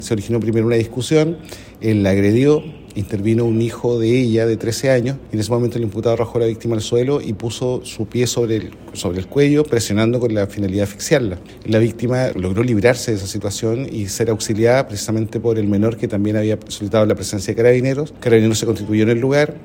Así fue precisado a Radio Bío Bío por el fiscal del Ministerio Público en Temuco, Juan Pablo Salas .